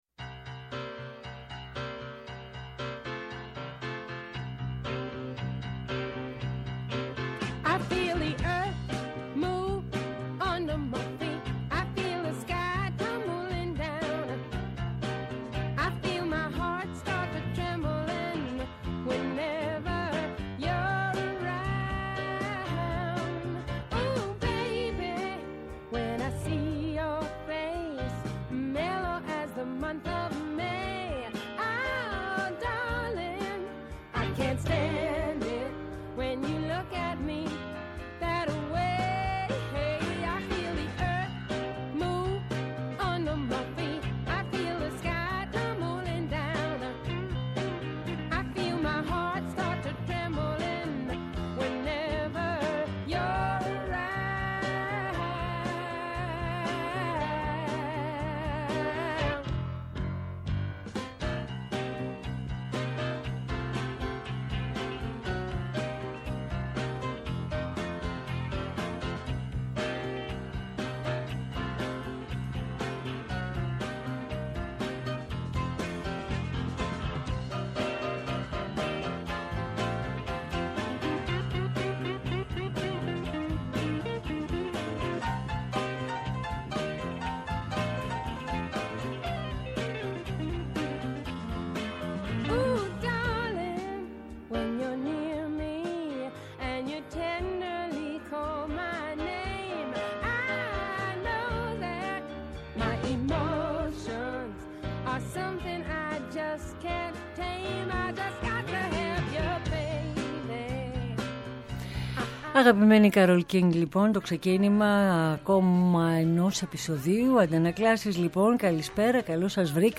Ένα παζλ ήχων δημιουργούν μοναδικές πολιτιστικές σελίδες.